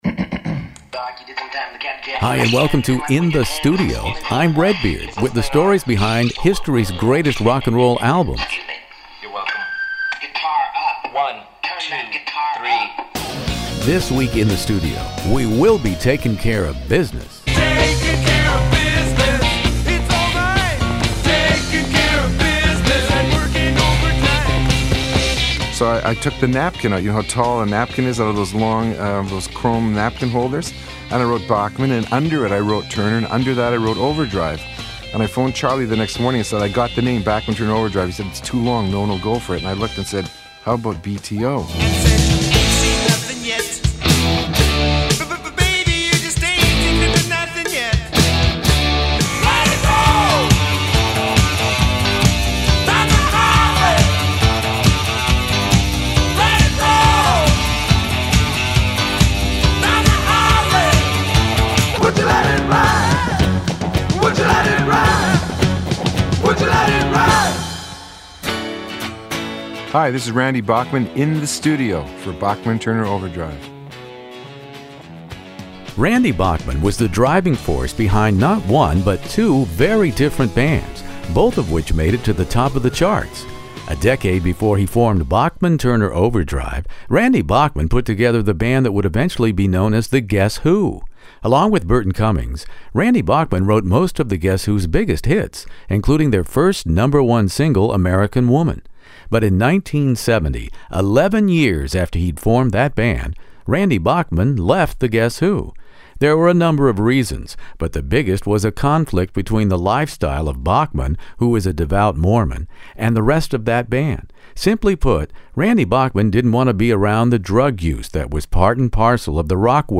Randy Bachman joins me for a delightful classic rock interview covering the May 1973 debut, then BTO II , the #1-seller Not Fragile , and Four Wheel Drive all in barely two years.